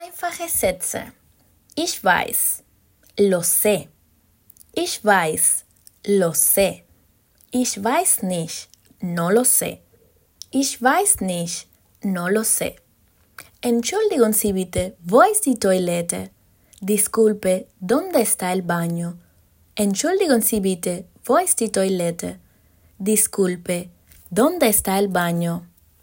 Und hier kannst du in eine Audiolektion hineinhören: